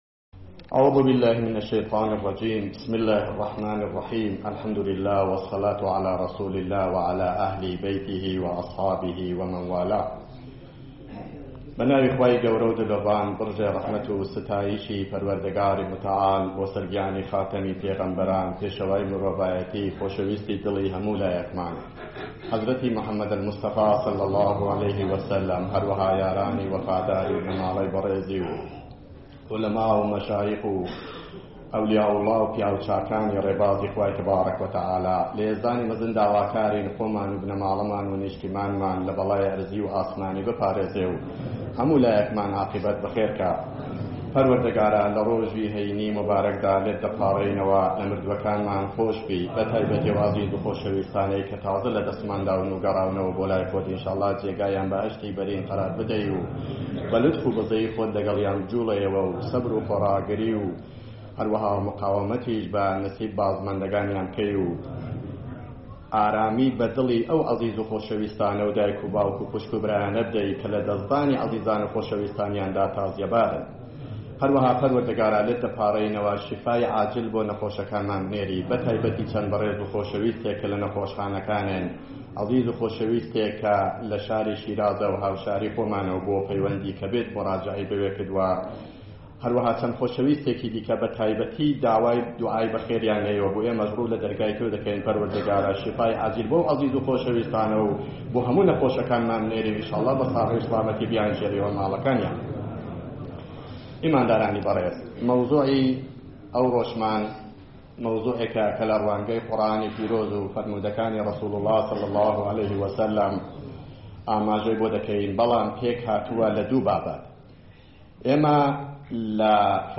به گزارش خبرنگار ایرنا، ماموستا عبدالسلام امامی در خطبه‌های نماز جمعه این هفته مهاباد، با اشاره به مهمانی‌ها و دورهمی‌های شب یلدا اظهار کرد: زیاده‌روی در پذیرایی‌ها سبب شده است که برخی خانواده‌ها توان میزبانی نداشته باشند و همین موضوع به کمرنگ شدن دید و بازدیدها منجر شده است.